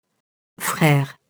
frère [frɛr]